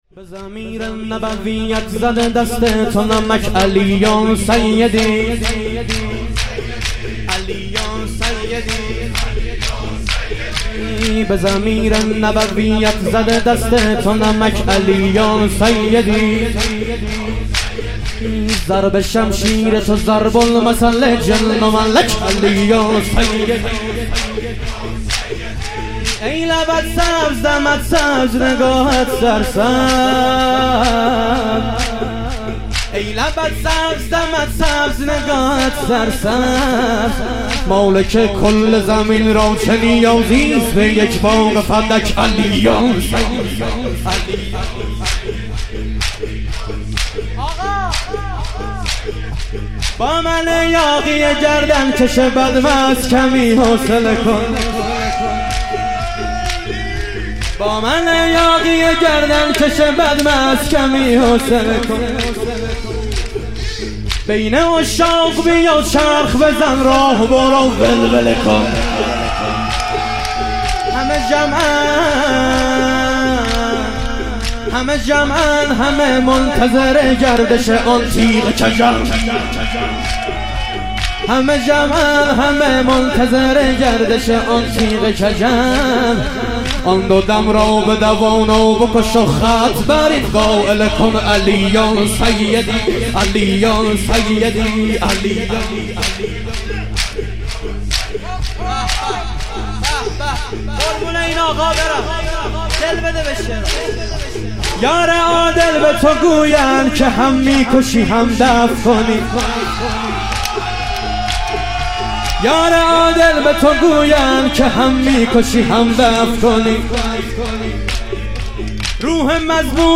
ظهور وجود مقدس امام حسن عسکری علیه السلام - شور